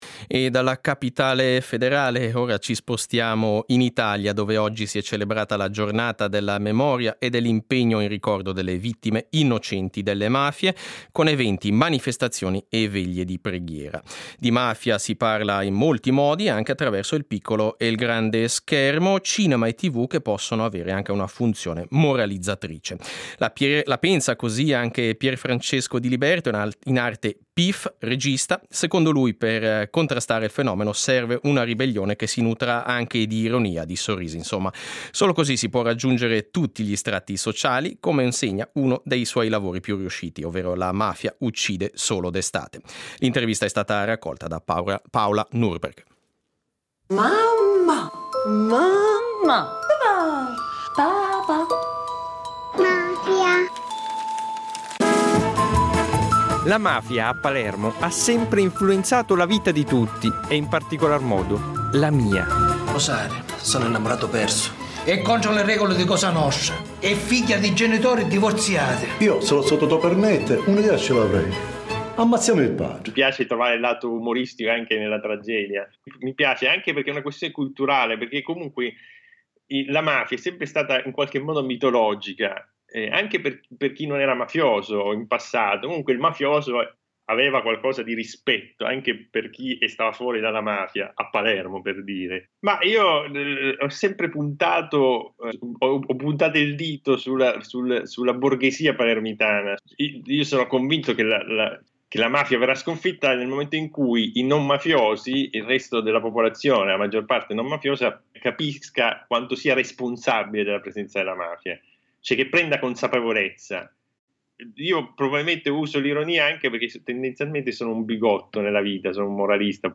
SEIDISERA del 21.03.22: Mafia: intervista al regista Pierfrancesco Diliberto